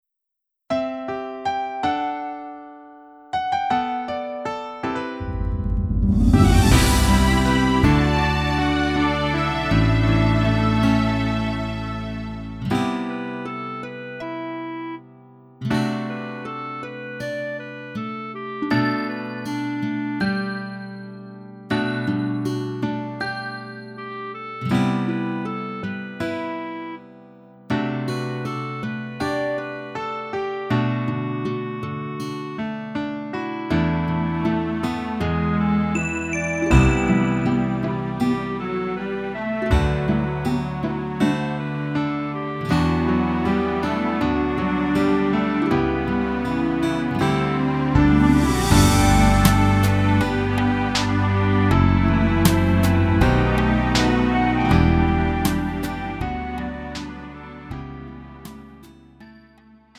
음정 -1키 4:15
장르 가요 구분 Lite MR
Lite MR은 저렴한 가격에 간단한 연습이나 취미용으로 활용할 수 있는 가벼운 반주입니다.